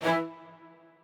strings6_40.ogg